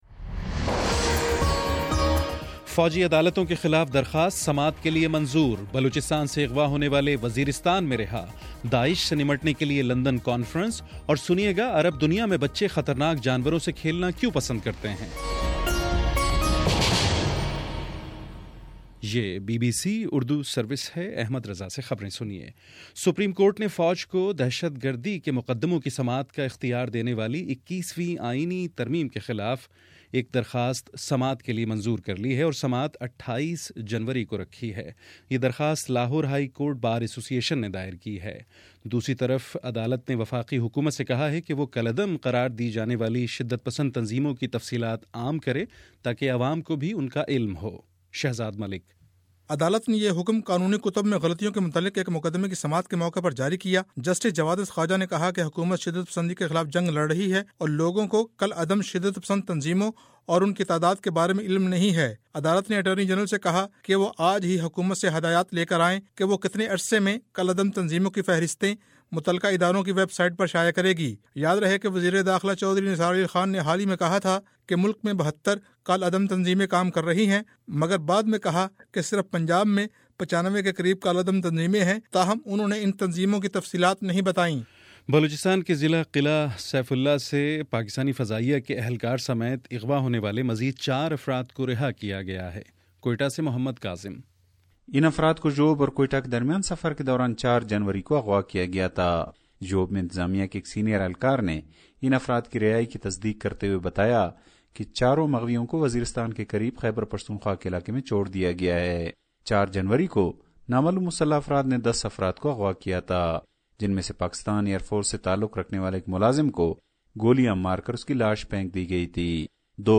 جنوری 22: شام سات بجے کا نیوز بُلیٹن